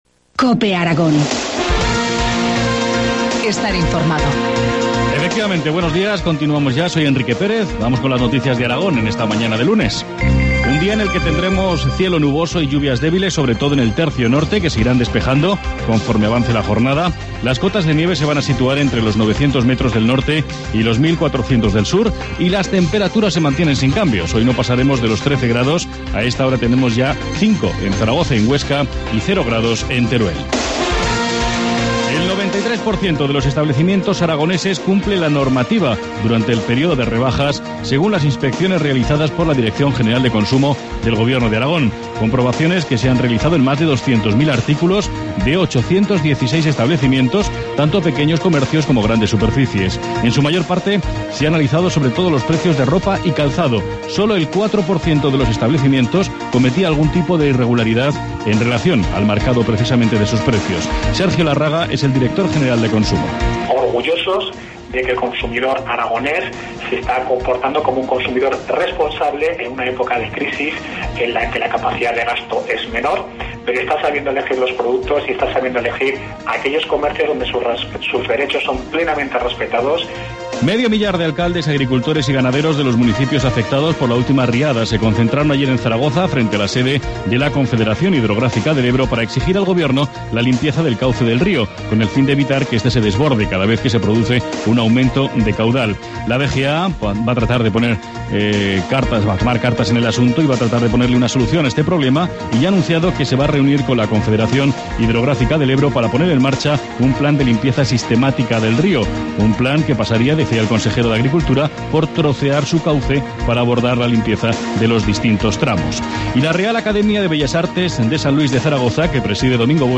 Informativo matinal, lunes 28 de enero, 8.25 horas